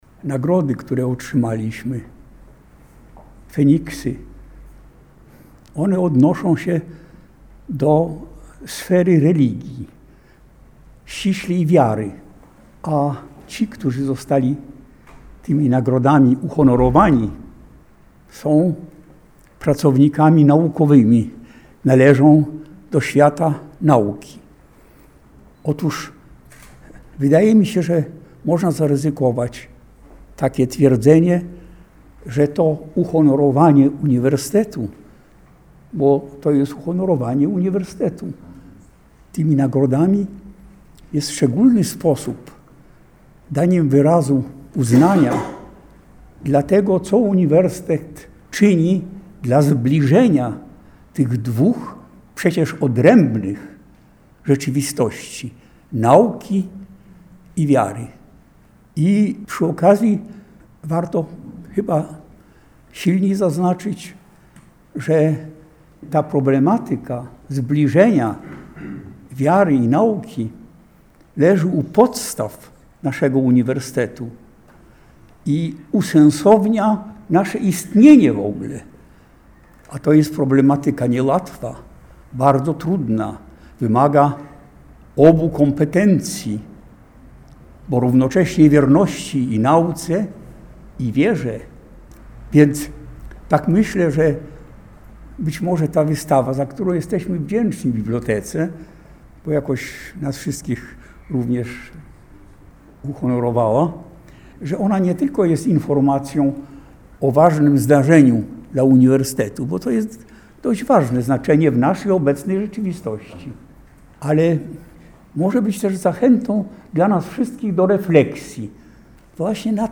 Wystąpienia laureatów